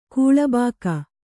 ♪ kūḷa bāka